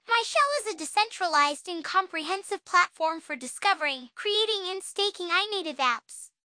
On the website, it is more accurate to the voice than my local copy; I'm pretty sure I followed USAGE.md to the letter...